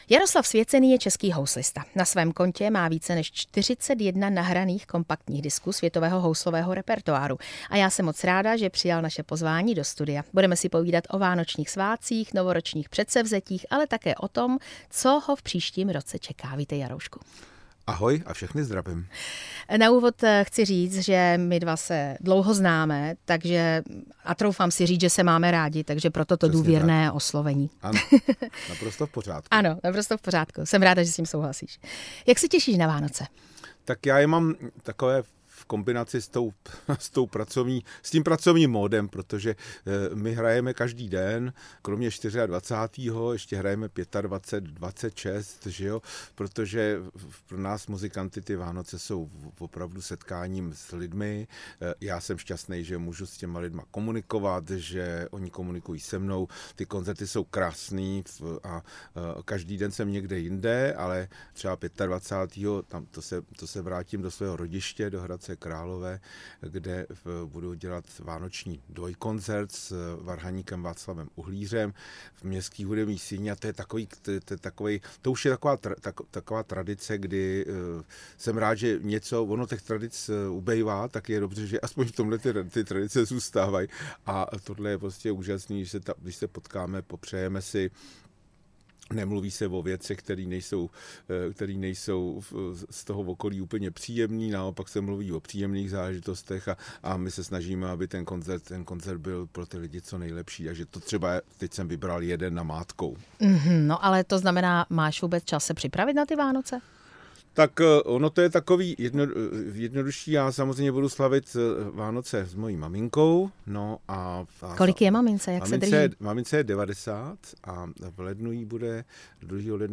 Český houslista Jaroslav Svěcený tráví Vánoce koncertováním i v rodinném kruhu.
Jaroslav Svěcený ve vysílání Radia Prostor